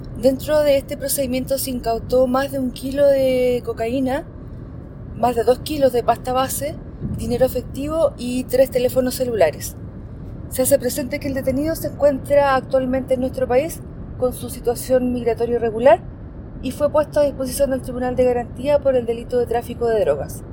Así lo precisó la comandante, Ximena Valle, prefecta en Cautín.